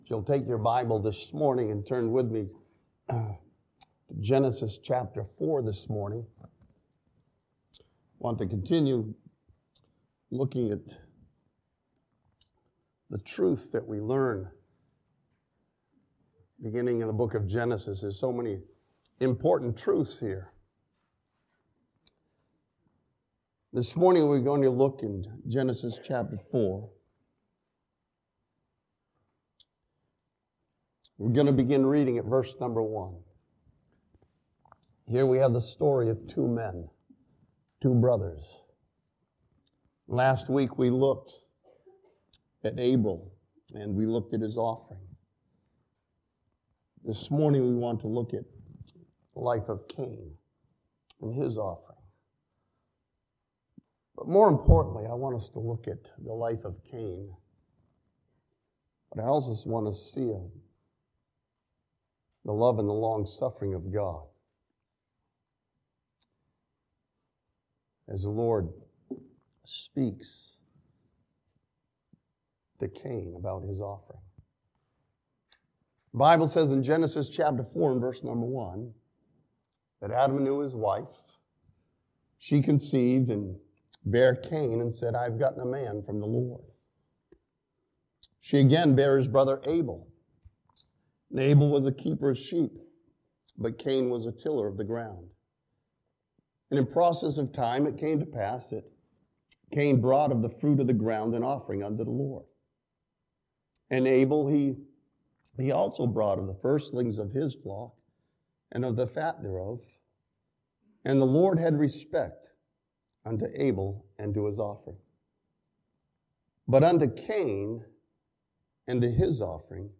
This sermon from Genesis chapter 4 challenges believers to see Cain's offering and rejoice in the offering of Jesus for us.